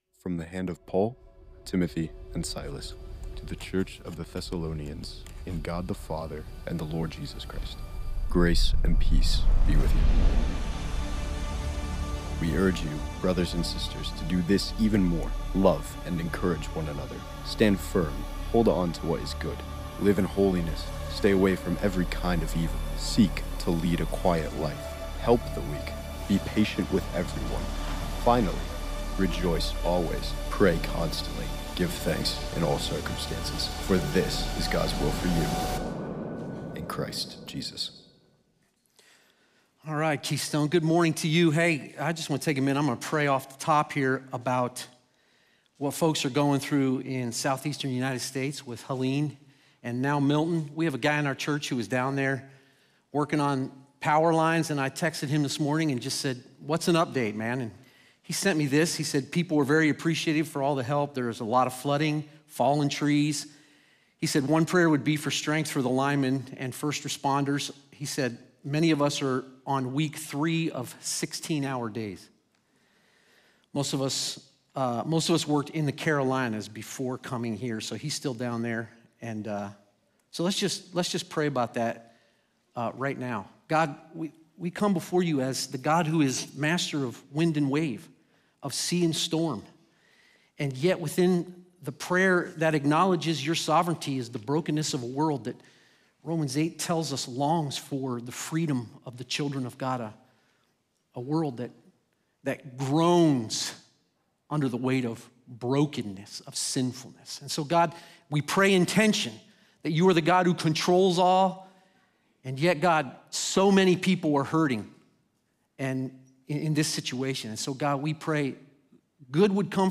In this week's sermon